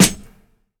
GUFSH_SNR2.wav